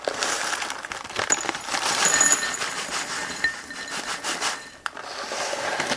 液体的声音 " 在玻璃杯里倒水
描述：把水倒进一个装满冰的玻璃杯子里。使用Sony IC录音机录制，使用Edison在Fl Studio中处理。
标签： 玻璃杯 浇注 放松 液体 索尼集成电路（IC）记录器
声道立体声